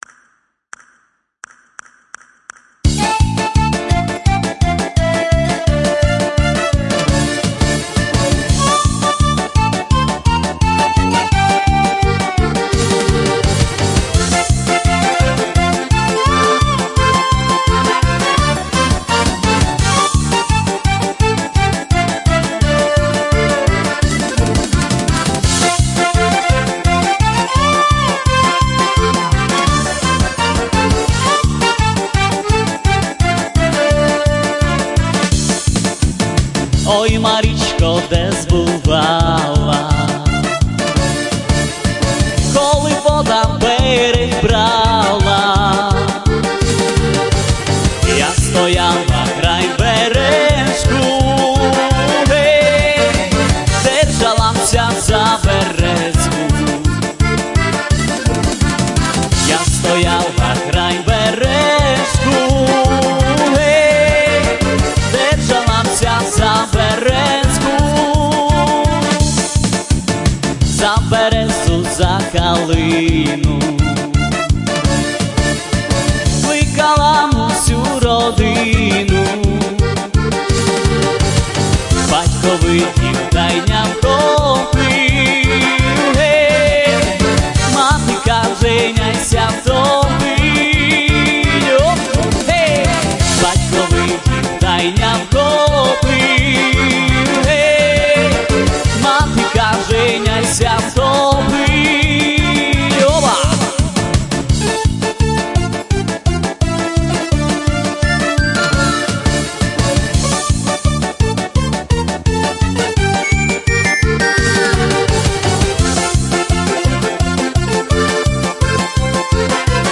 Всі мінусовки жанру Polka
Плюсовий запис